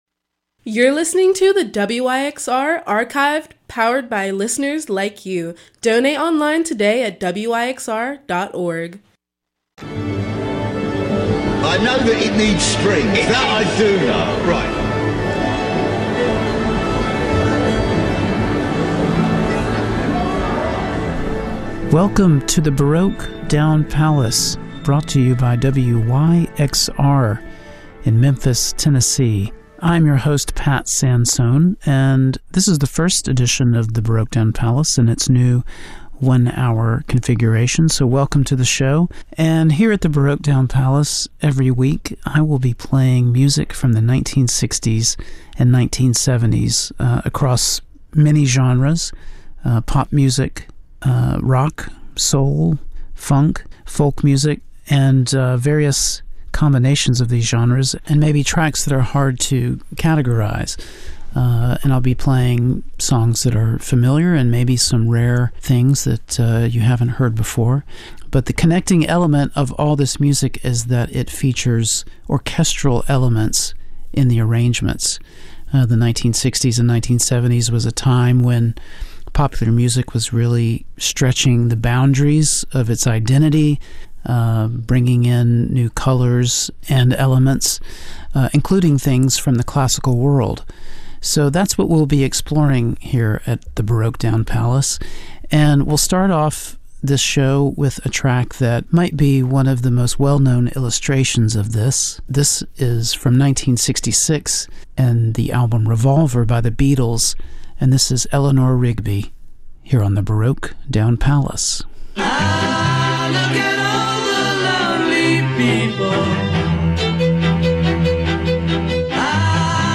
Pat Sansone (Wilco, Autumn Defense, Mellotron Variations, et al) plays both well-known and obscure tracks from the 1960s and 1970s across several genres, with a focus on tracks that incorporate symphonic touches and/or influence from the classical world.